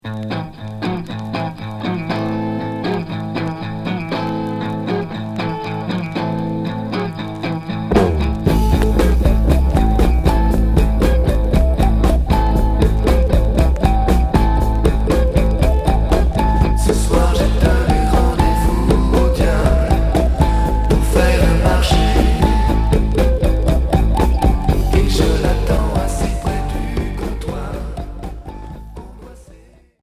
Heavy rock Septième 45t retour à l'accueil